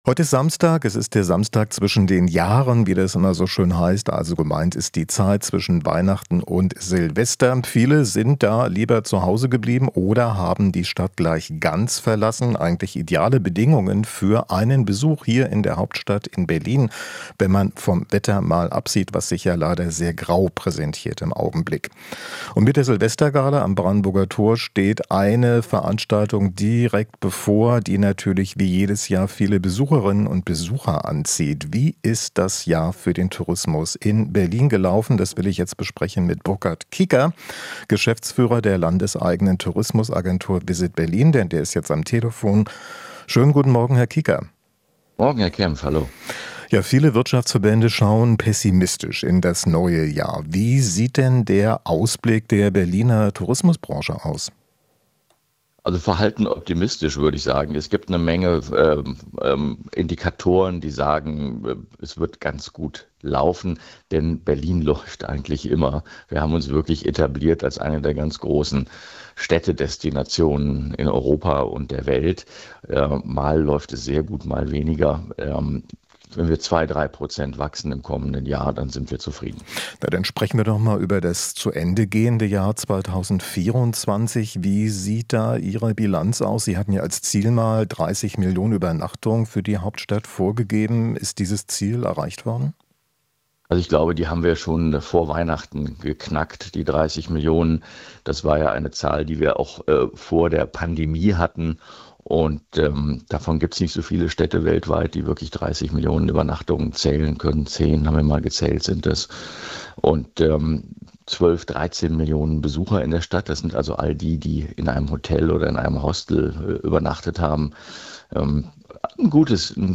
Interview - Berliner Tourismusbranche "verhalten optimistisch" für 2025